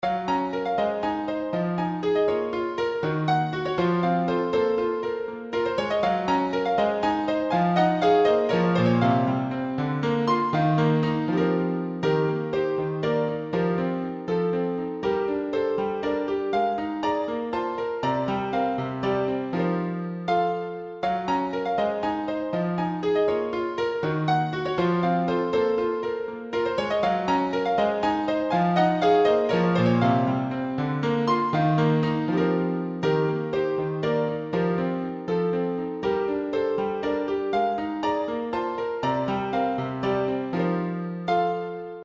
インストゥルメンタルショート